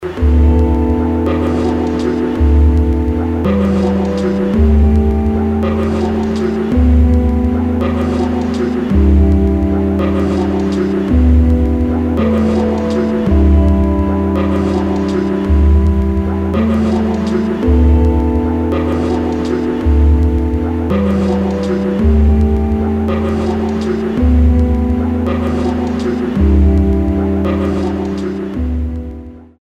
[ DOWNBEAT / BASS / EXPERIMENTAL ]